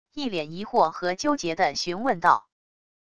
一脸疑惑和纠结地询问到wav音频